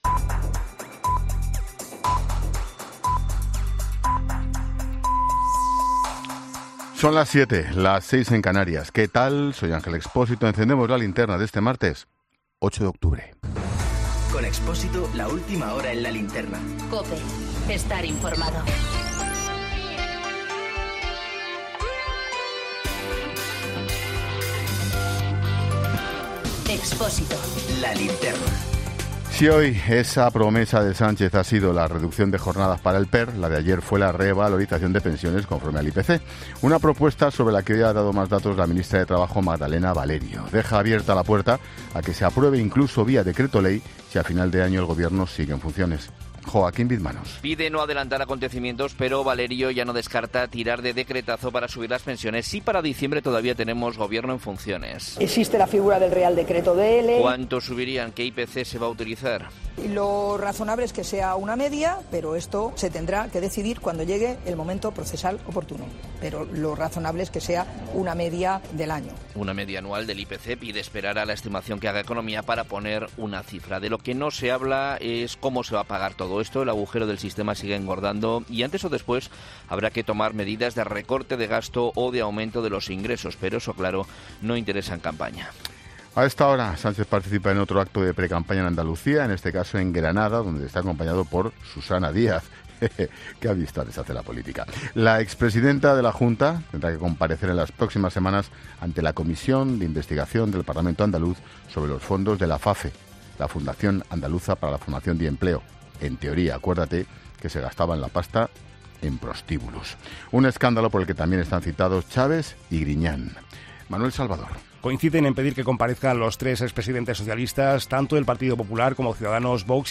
Boletín informativo de COPE del 8 de octubre de 2019 a las 19 horas